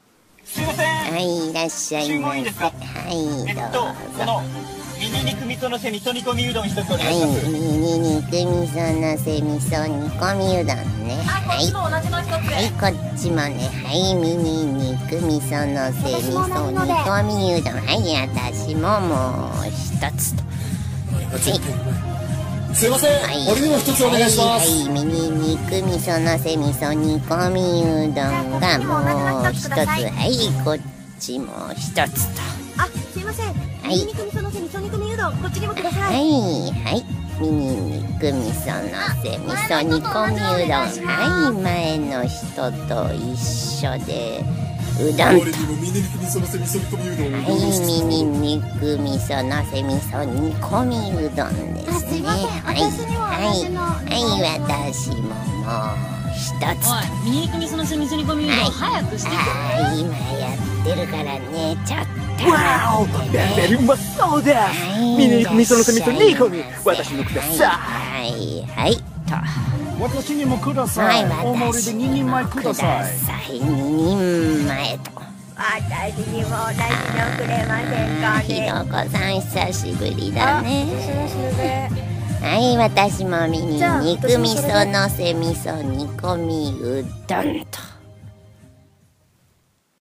うどん屋の客達 × 店員 (湯婆婆)